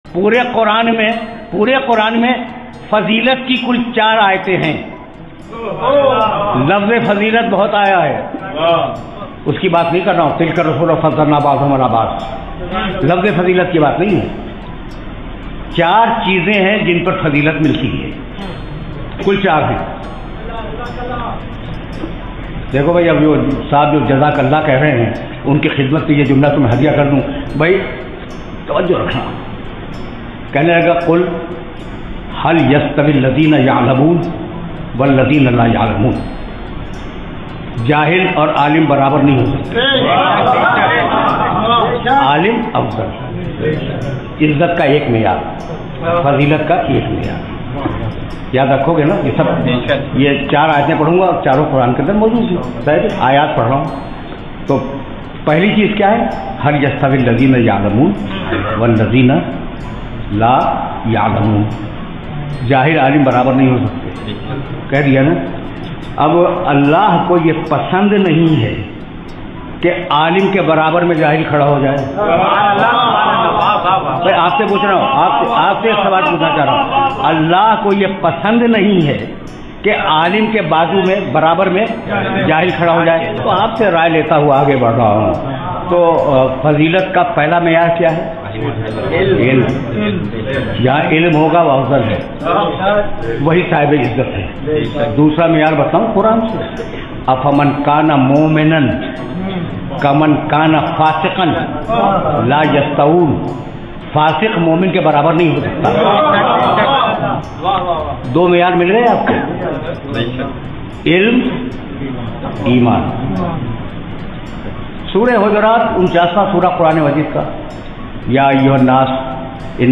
اسپیکر: علامہ طالب جوہری